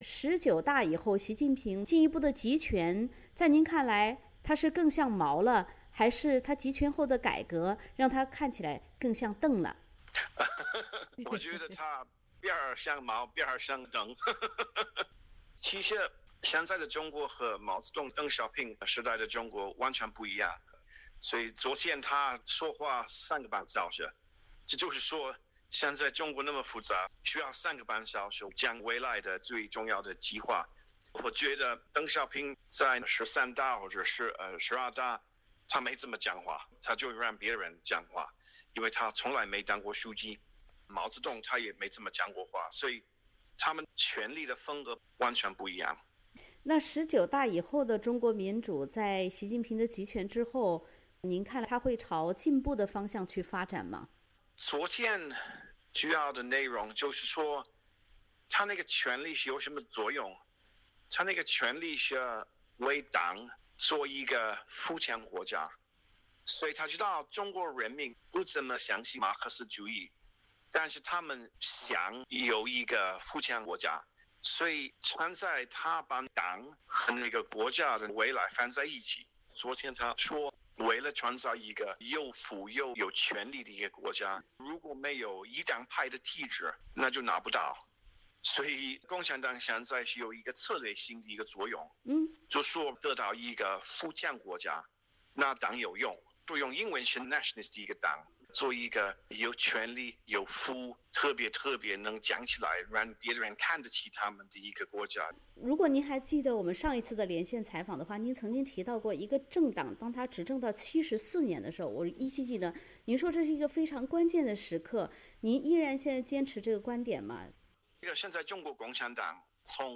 Source: AP SBS 普通话电台 View Podcast Series Follow and Subscribe Apple Podcasts YouTube Spotify Download (4.41MB) Download the SBS Audio app Available on iOS and Android 作为当今世界最重大的政治事件之一，中国共产党第十九次代表大会10月18日在北京召开。